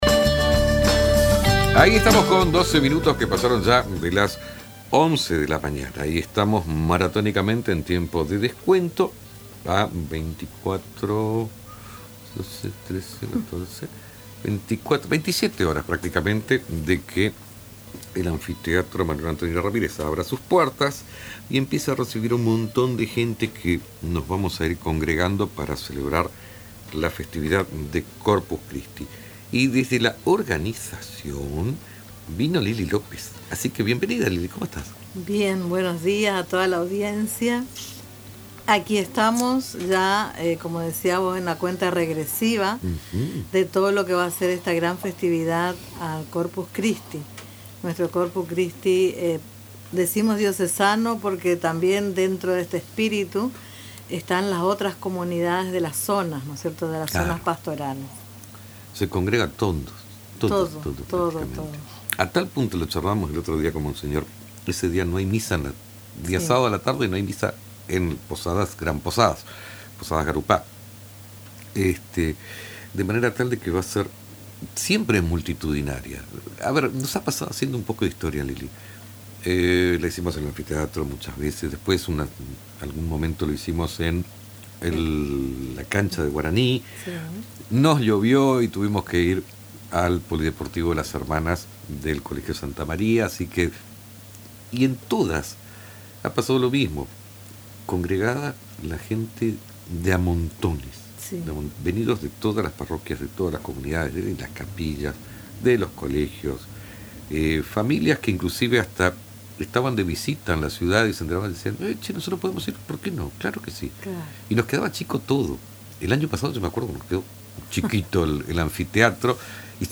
compartió detalles exclusivos en una entrevista con Radio Tupa Mbae.